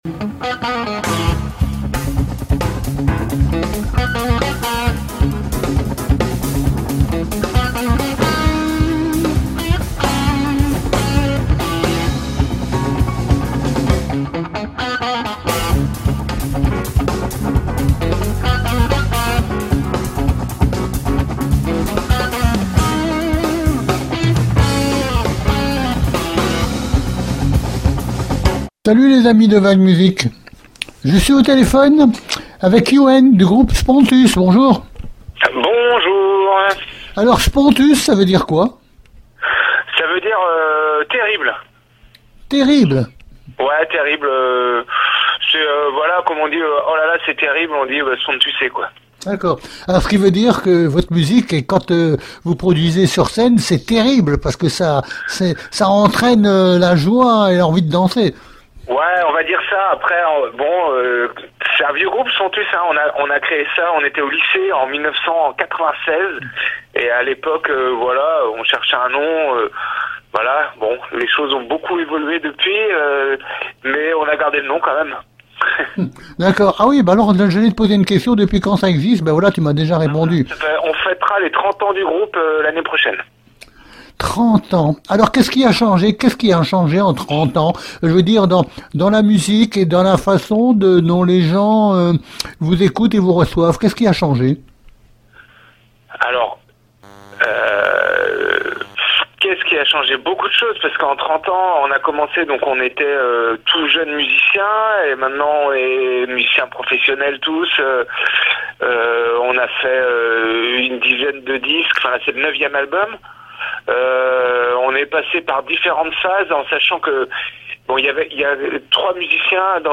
SPONTUS-VAG MUSIC Interview du 23 juin 2025